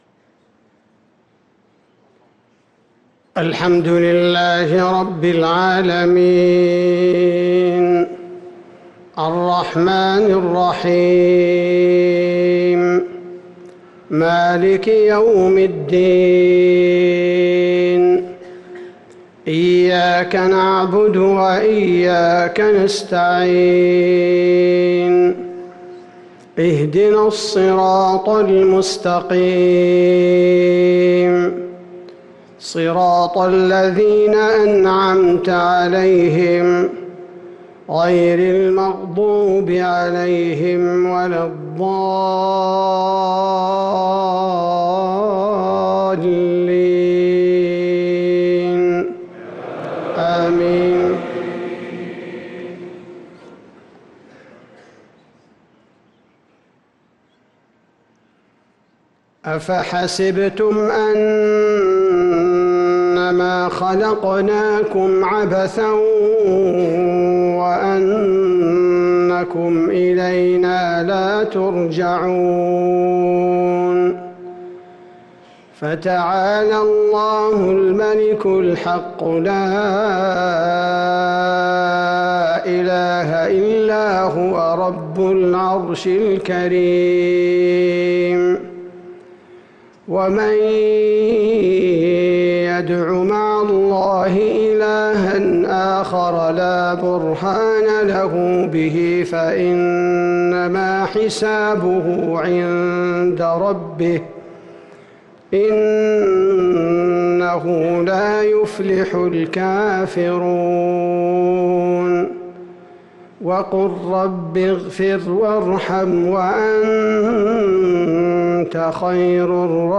صلاة المغرب للقارئ عبدالباري الثبيتي 13 محرم 1445 هـ